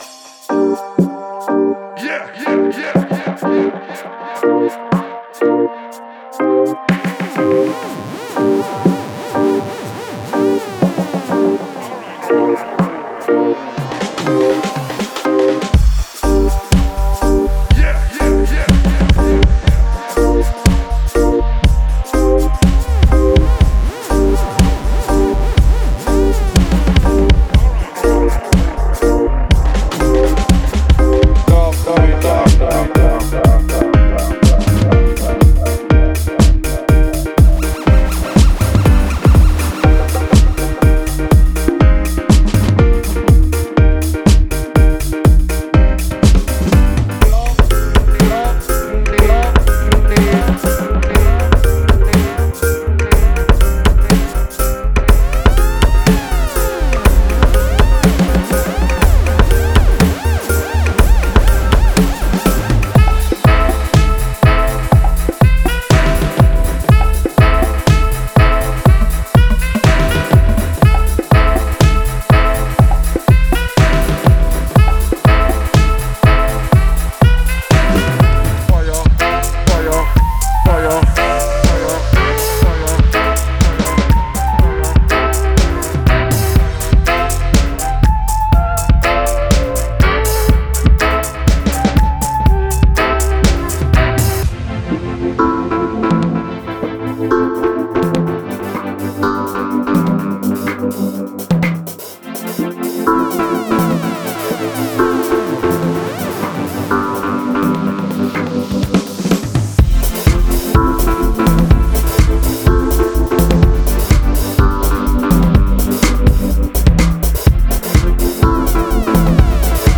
Genre:Dub